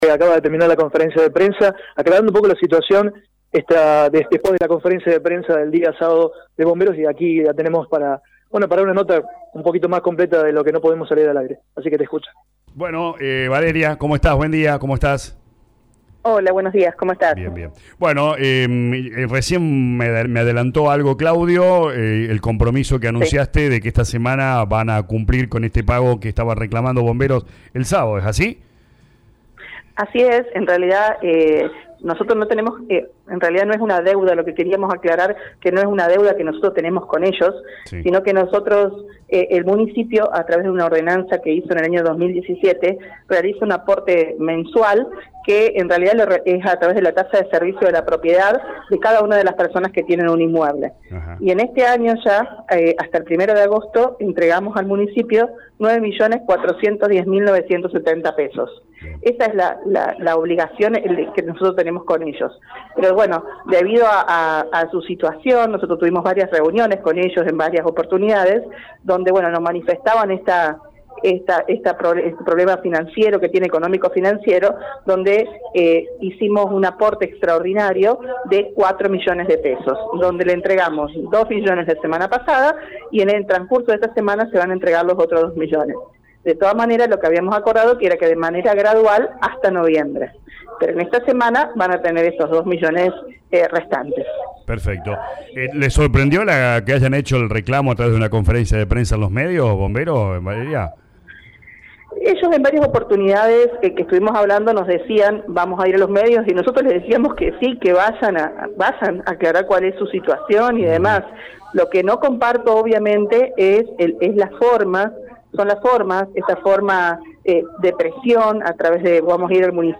«Debido a su situación, manifestaban el problema financiero y el aporte entregado fue por $2M, pero en esta semana tendrán los $2M restante, si bien se había acordado hacerlo gradual hasta noviembre», explicó Valeria Gandino en diálogo con LA RADIO 102.9.